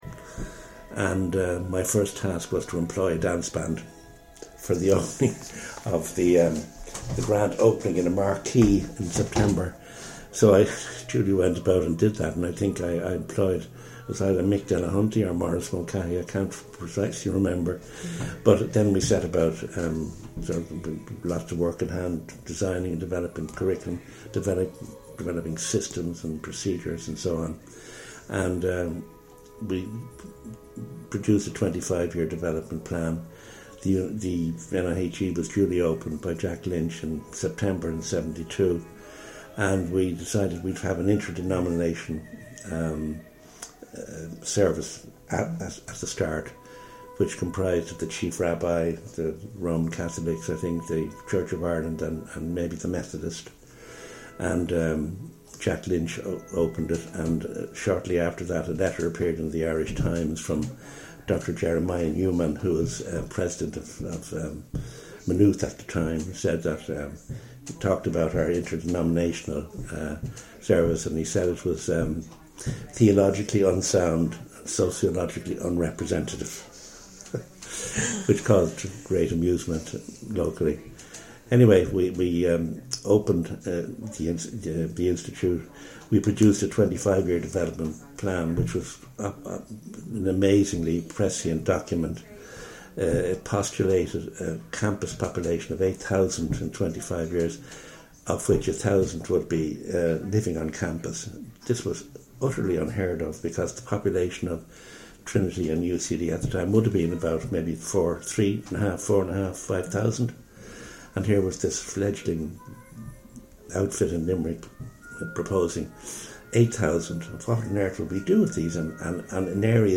-Interview excerpt
Oral histories